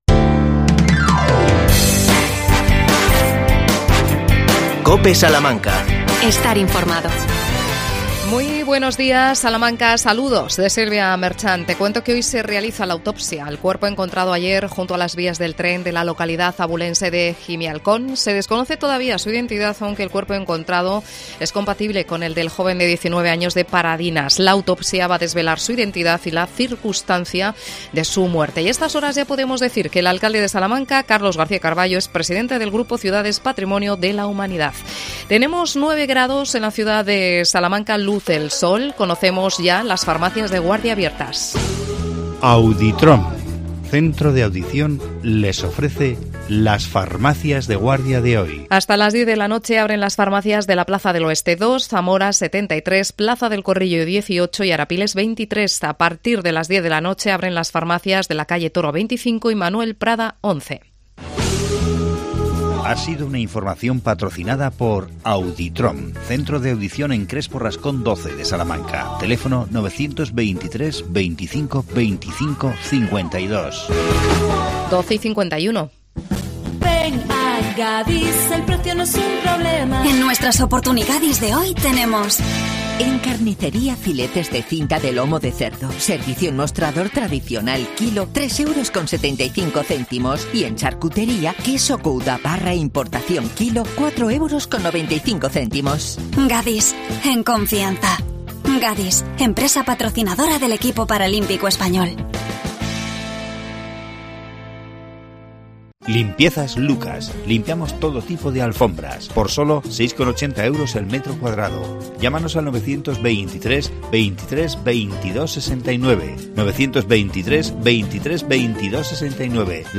Entrevistamos al concejal de Tráfico Fernando Carabias sobre obras en calles y cambio de la iluminación artística en la Plaza Mayor.